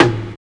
108 TOM MD-L.wav